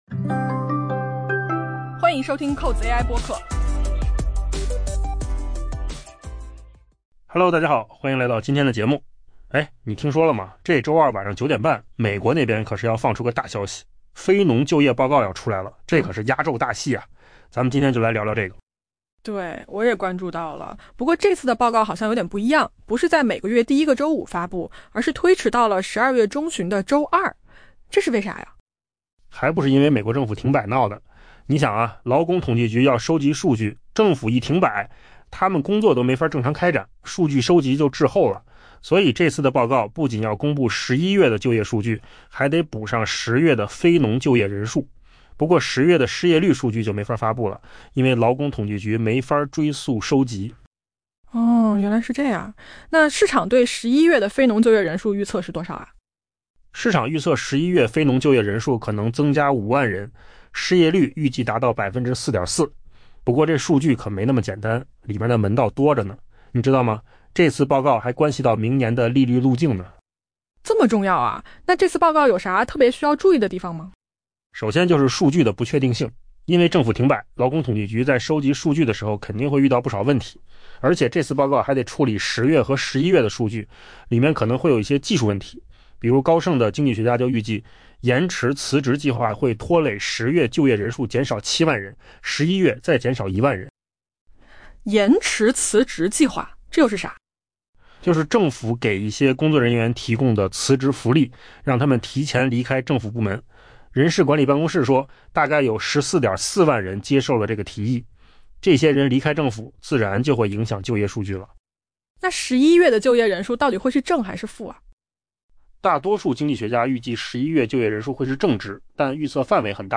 AI 播客：换个方式听新闻 下载 mp3 音频由扣子空间生成 本周，美国经济数据扎堆发布，但定于北京时间周二晚 9 点 30 分公布的非农就业报告将占据 「 舞台中心。